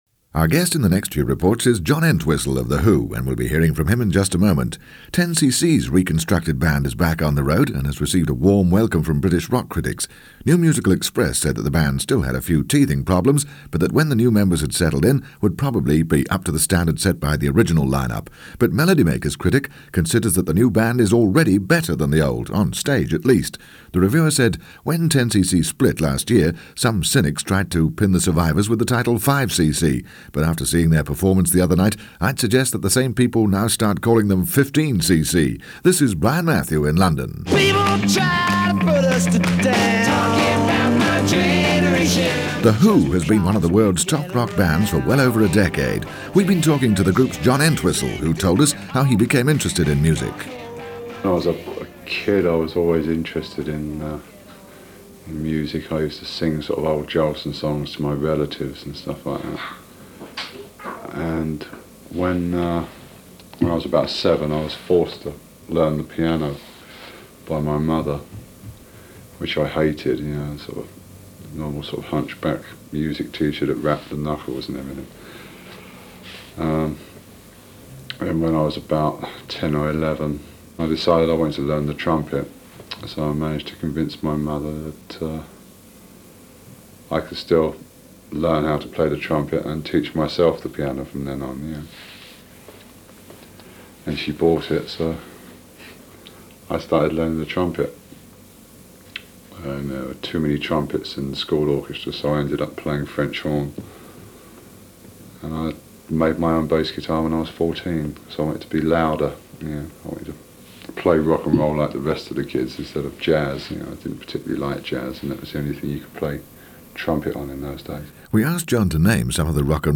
I ran across this interview (one of the rare ones I understand) with Who bassist John Entwistle , done by the BBC and put together for their syndicated package London Report, which originally aired during the week of June 9, 1977.
london-report-john-entwistle-june-1977.mp3